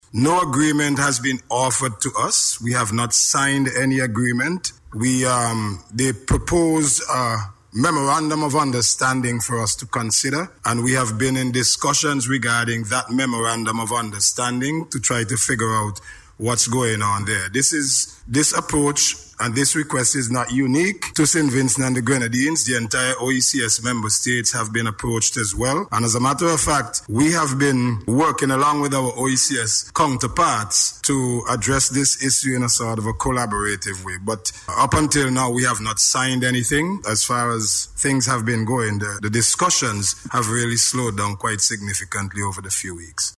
Minister Bramble made the announcement as he responded to a question in Parliament last week.